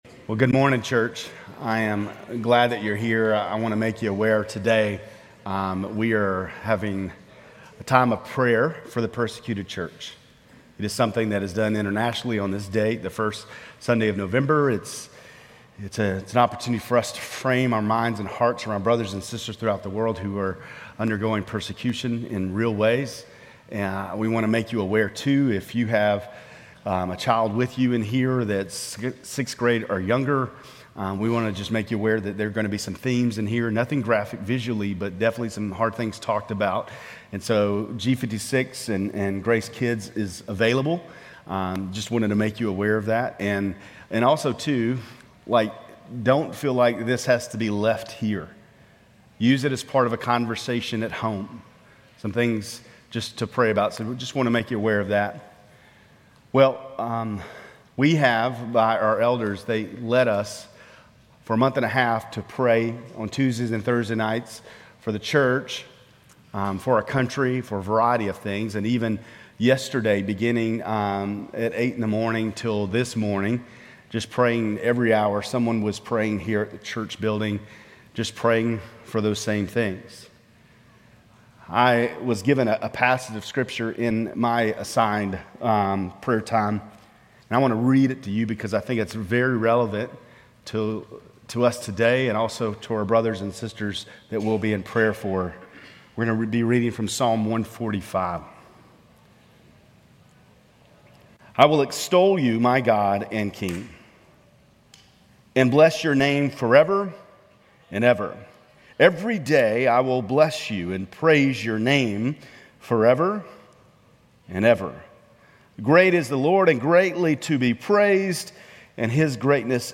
Grace Community Church Lindale Campus Sermons International Day of Prayer for the Persecuted Church Nov 03 2024 | 00:26:47 1x 00:00 / 00:26:47 Subscribe Share RSS Feed Share Link Embed